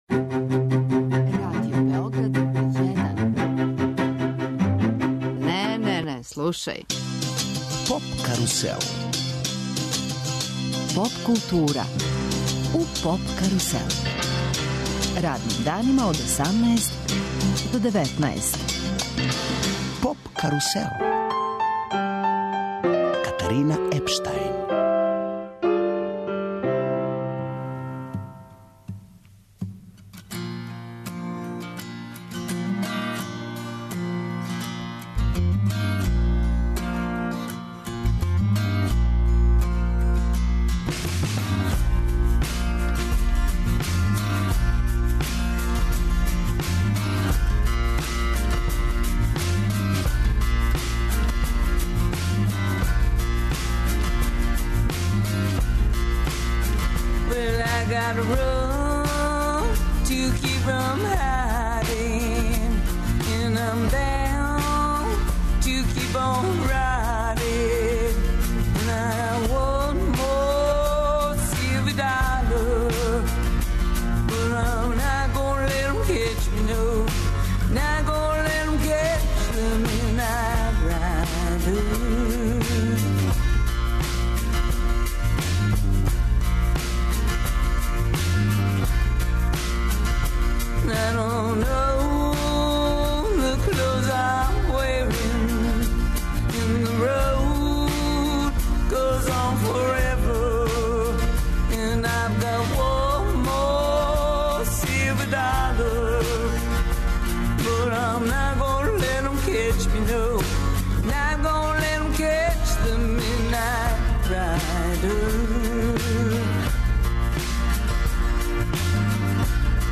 Гости емисије су чланови групе Straight Mickey and The Boyz.